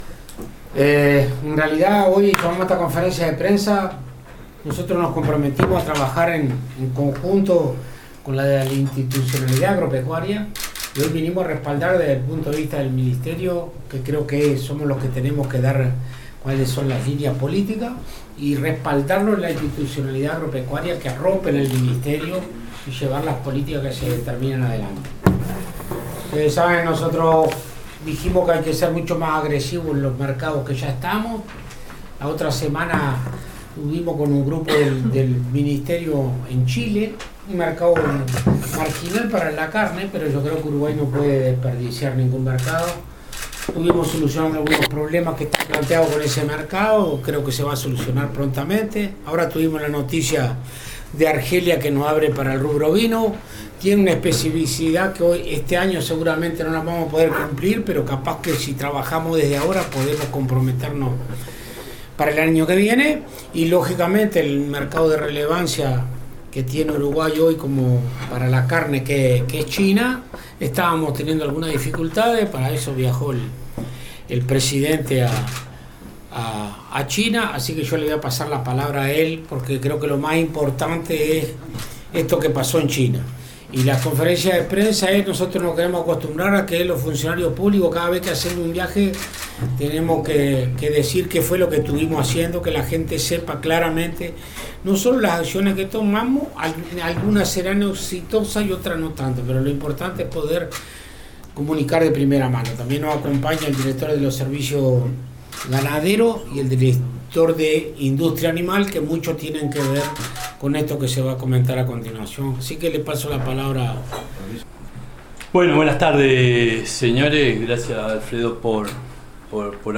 En conferencia de prensa, el ministro de Ganadería, Agricultura y Pesca Alfredo Fratti y el Presidente de INAC, explicaron la postura uruguaya desde que el Ministerio de Comercio de China comenzó a investigar la potencial aplicación de una salvaguarda al comercio de carne bovina.
audioconferencia.mp3